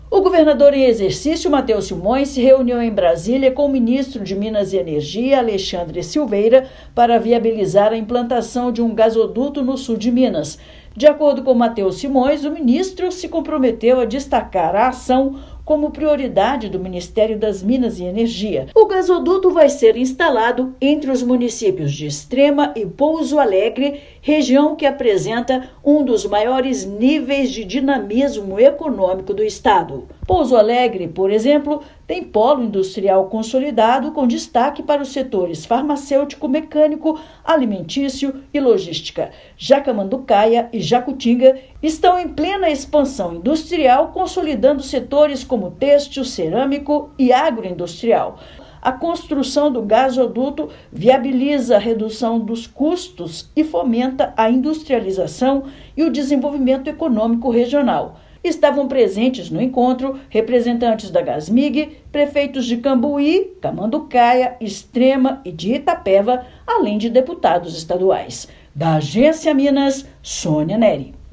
Expectativa dada pelo Governo Federal é de que as licenças tenham início em outubro deste ano. Ouça matéria de rádio.